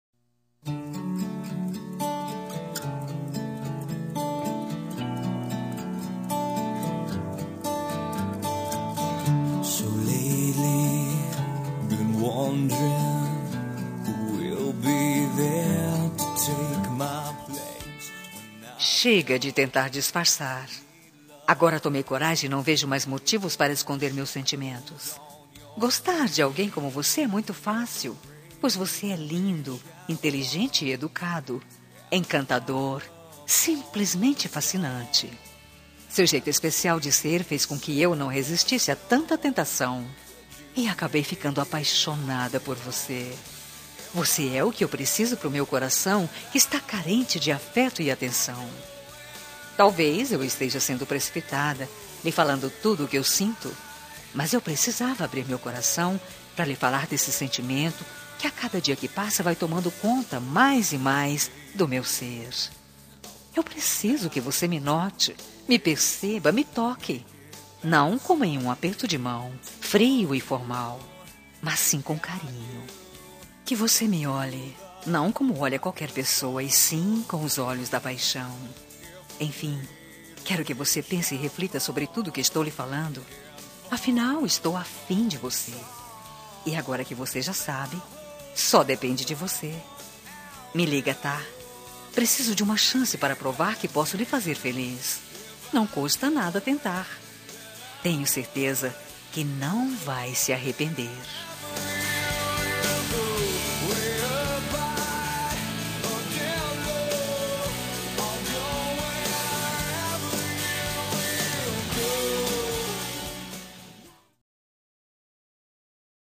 Telemensagem de Conquista – Voz Feminina – Cód: 140111